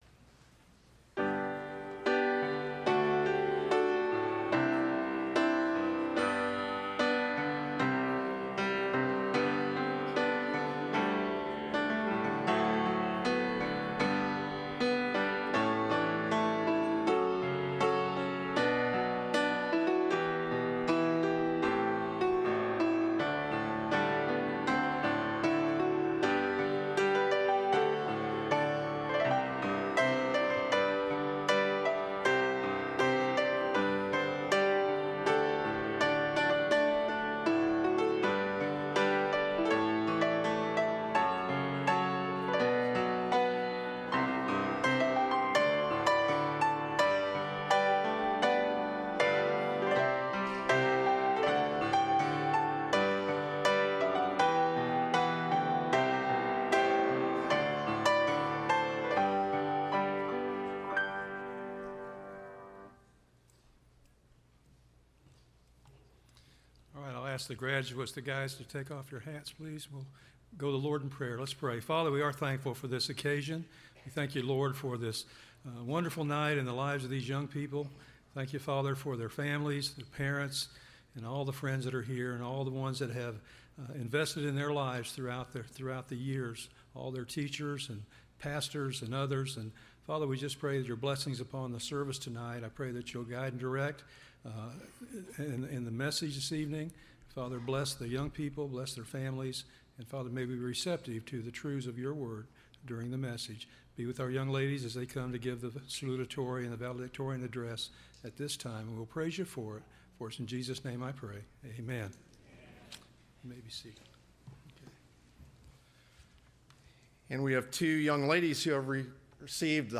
LBC Graduation 2018 – Landmark Baptist Church
Service Type: Wednesday